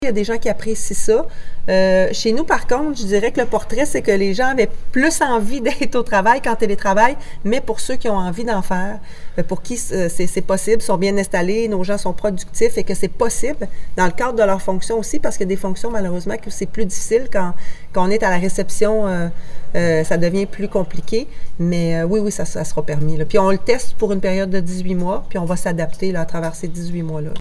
La mairesse Geneviève Dubois précise cependant qu’il sera possible de travailler à domicile de façon ponctuelle, lorsque des employés en manifestent le besoin.